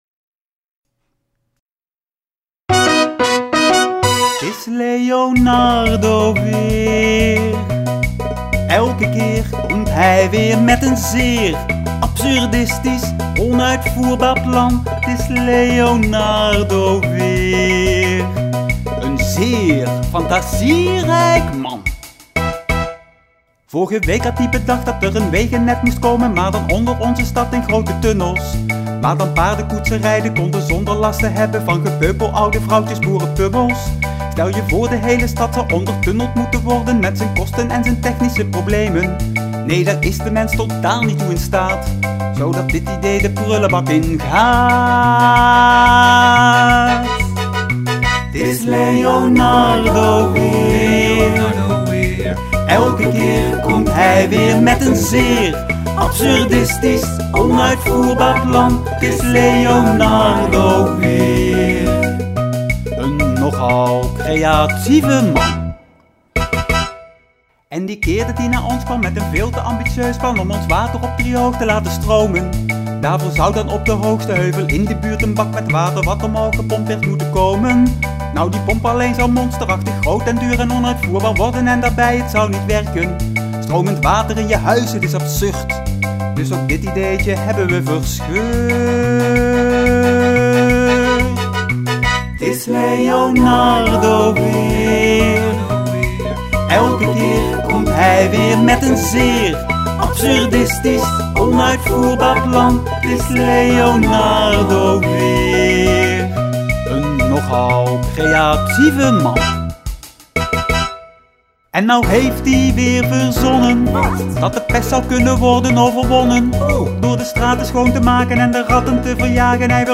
Uit  ‘Da Vinci” (musical)